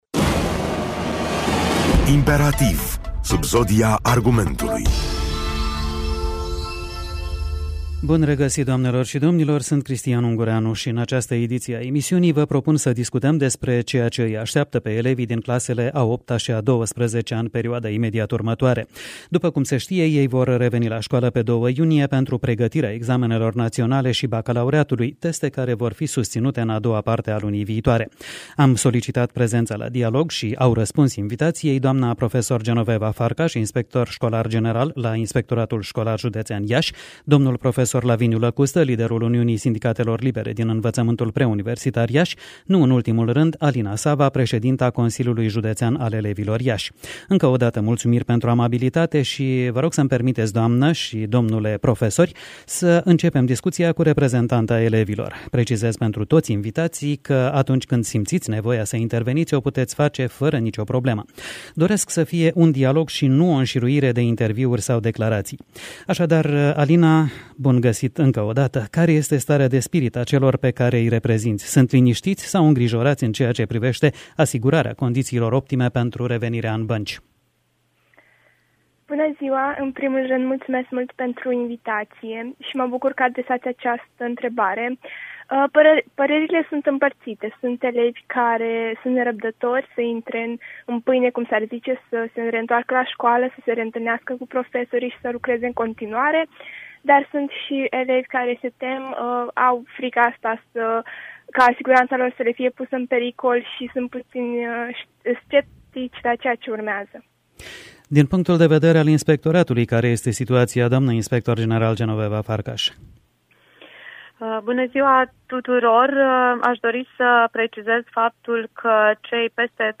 prin telefon